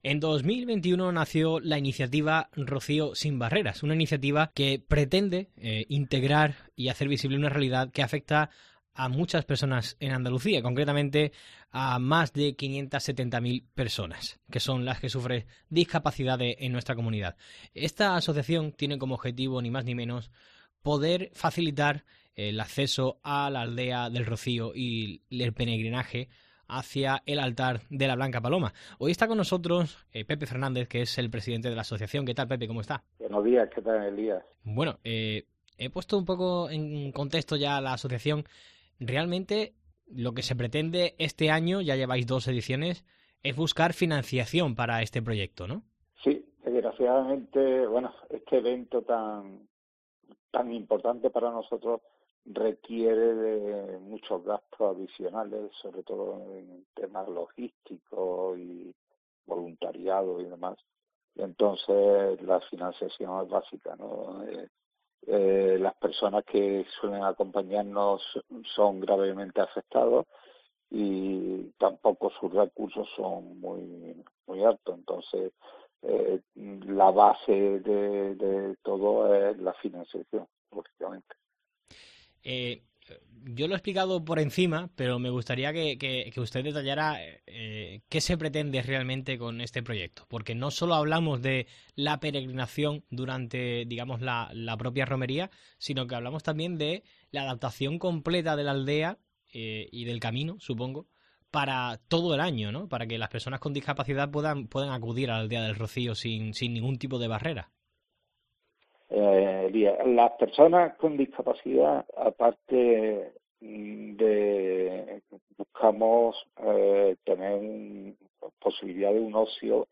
Asociación Rocío Sin Barreras, entrevista completa